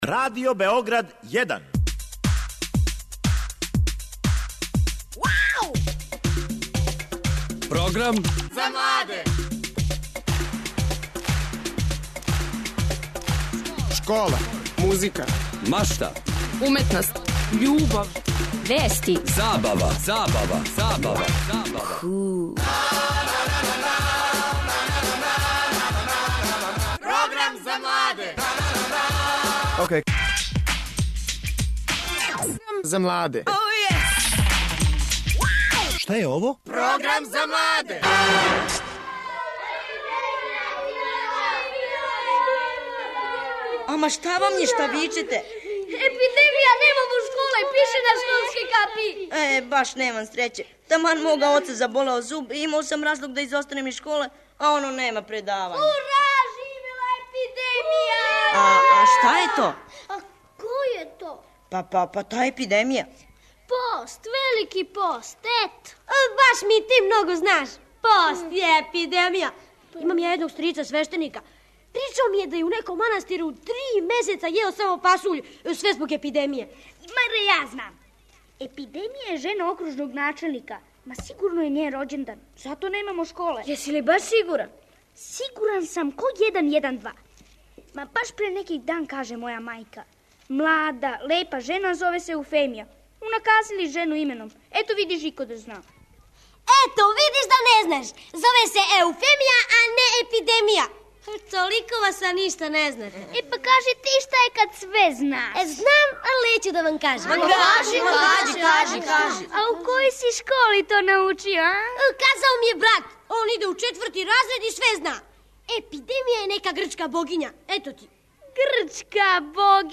(мала помоћ : смешно је све време, као и остали делићи које ћемо чути..гости су наставница и ђаци... наставница српског...)